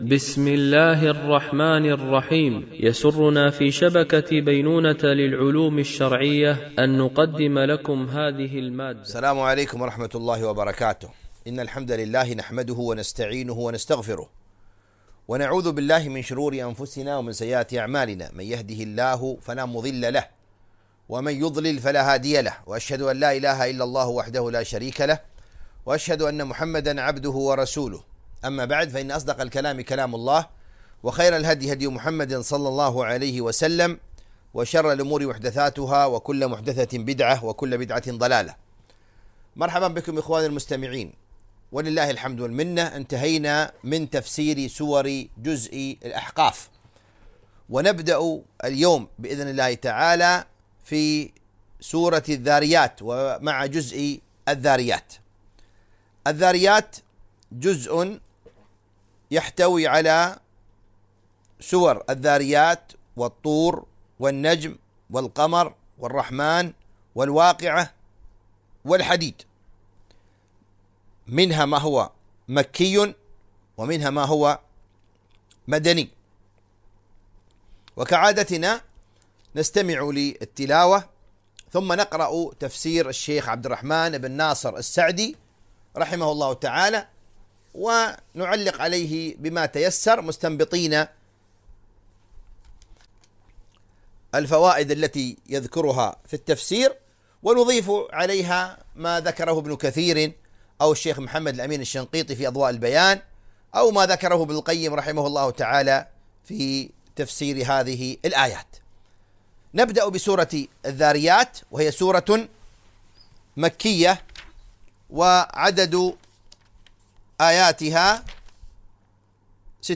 تفسير جزء الذاريات والأحقاف ـ الدرس 18 ( سورة الذاريات )